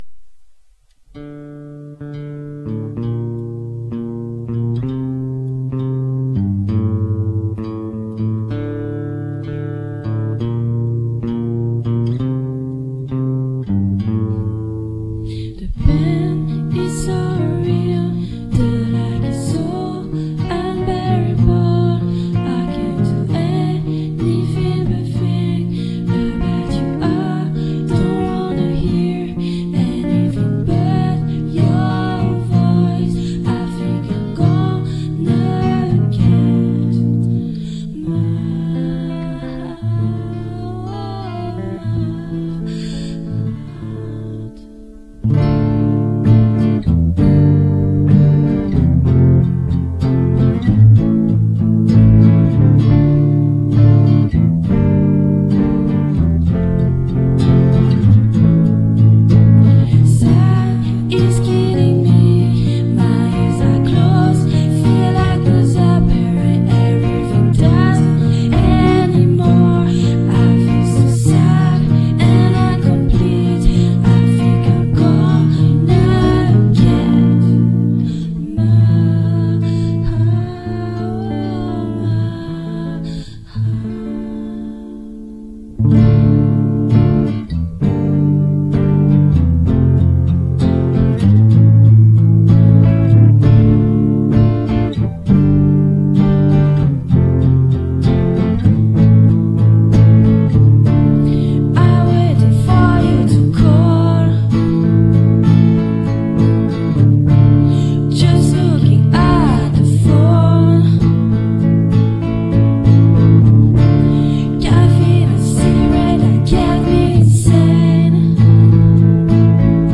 Acoustique